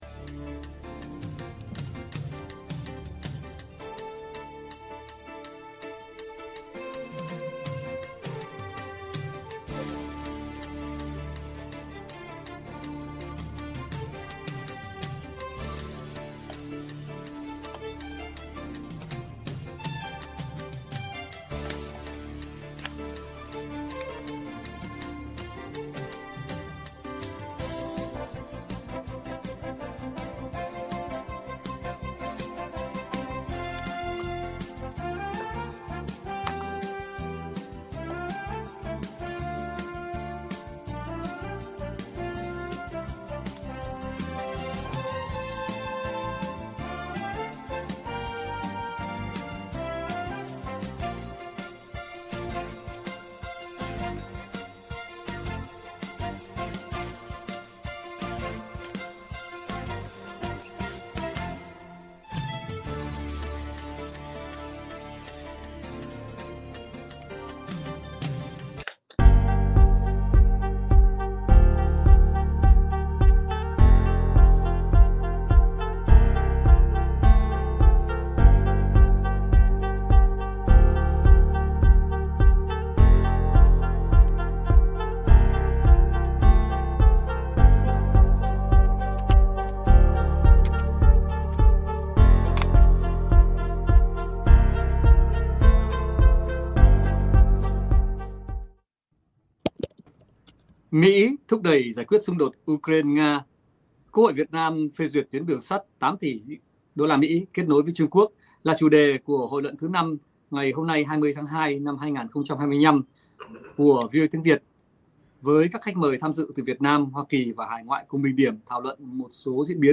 Các khách mời tham dự từ Hoa Kỳ và hải ngoại cùng bình điểm, thảo luận một số diễn biến, sự kiện và vấn đề nổi bật, được quan tâm trong tuần, kính thưa và kính chào quý vị khán, thính giả.